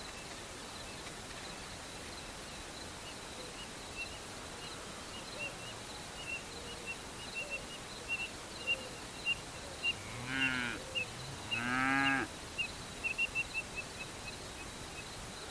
Colorada (Rhynchotus rufescens)
Nombre en inglés: Red-winged Tinamou
Localidad o área protegida: Saavedra - Partido de Saavedra
Condición: Silvestre
Certeza: Vocalización Grabada